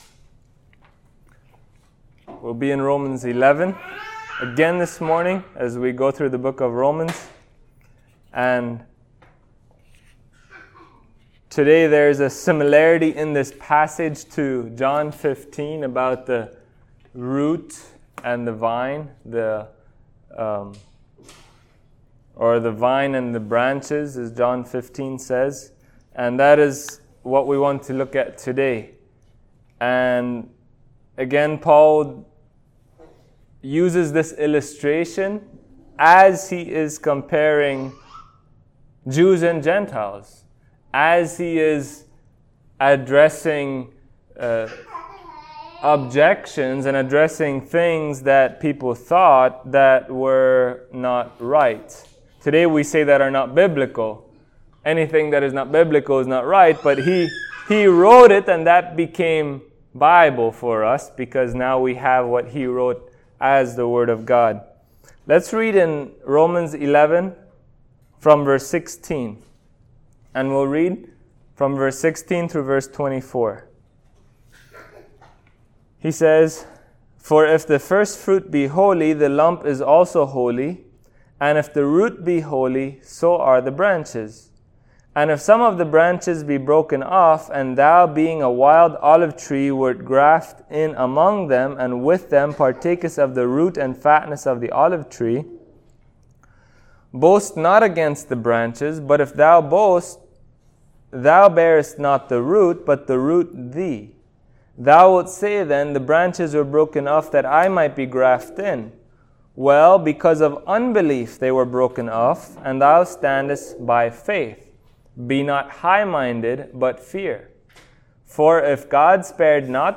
Passage: Romans 11:16-24 Service Type: Sunday Morning